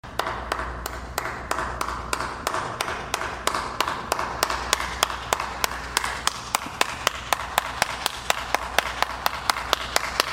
Shia Lebeouf Clapping